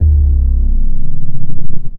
TSNRG2 Off Bass 024.wav